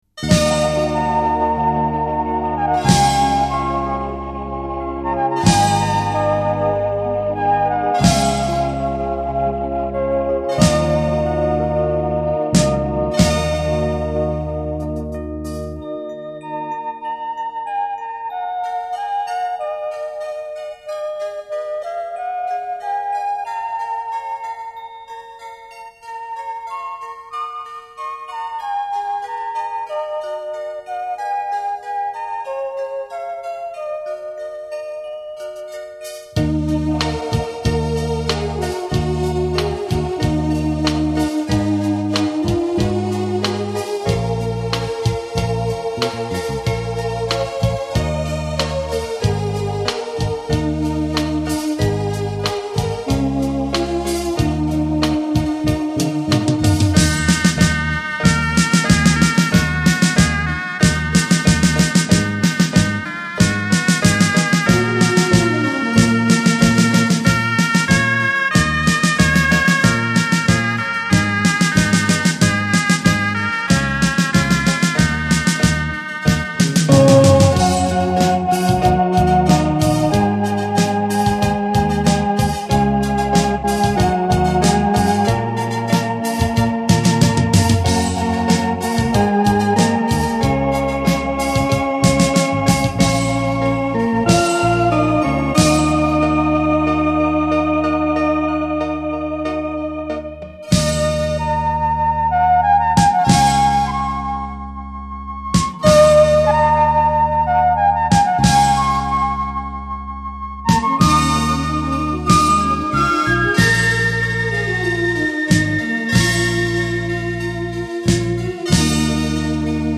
的一组电子琴音乐
激情，配器新颖流畅，是一张很好听的电子音